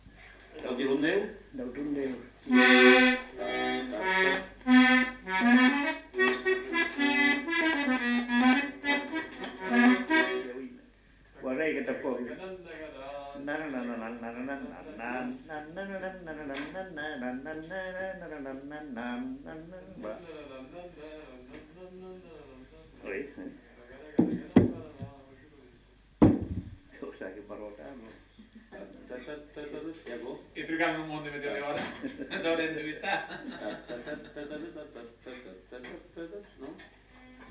Aire culturelle : Petites-Landes
Lieu : Lencouacq
Genre : chant
Effectif : 1
Type de voix : voix d'homme
Production du son : fredonné
Danse : rondeau